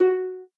minecraft / sounds / note / harp2.ogg
harp2.ogg